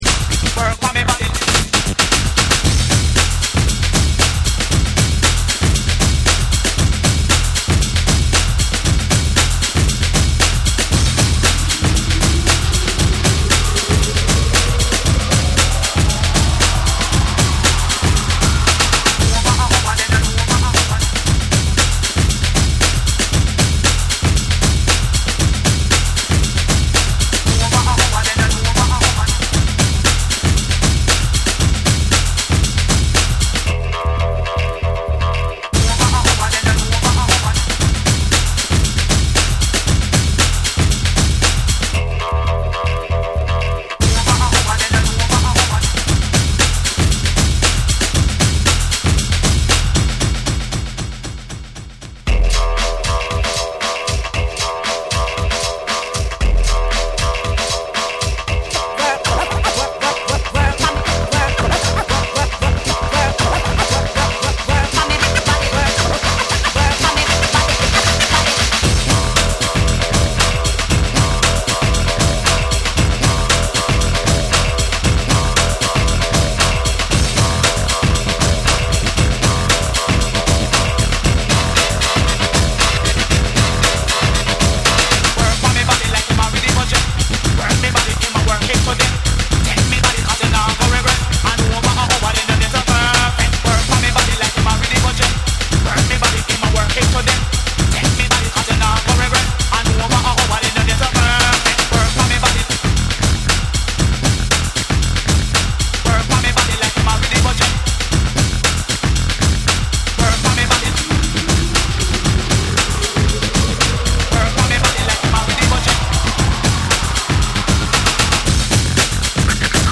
BREAKBEATS / REGGAE & DUB